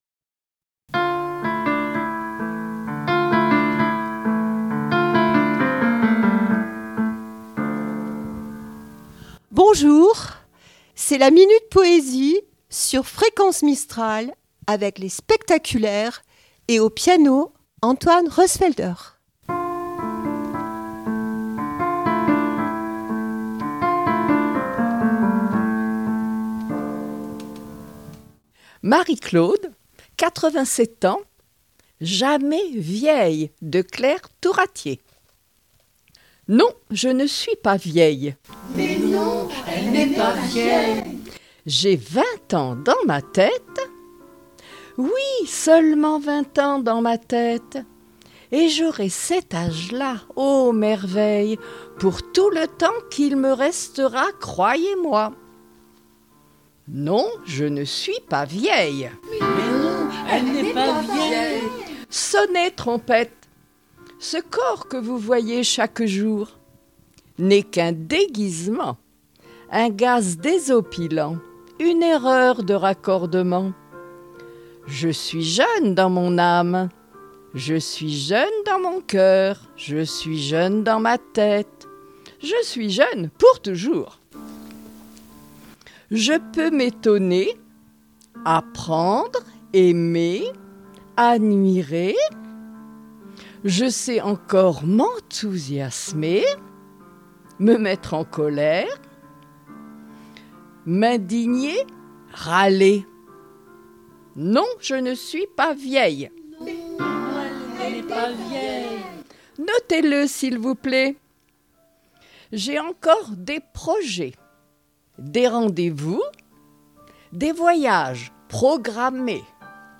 la poésie se murmure au rythme du piano sur Fréquence Mistral